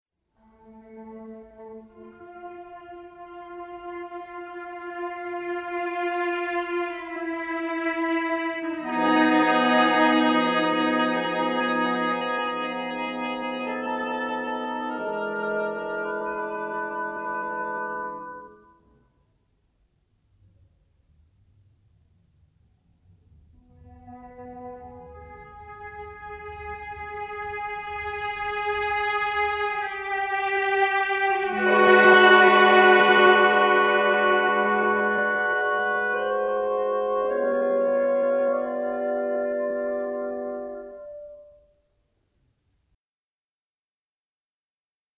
zwrot-tristanowski.mp3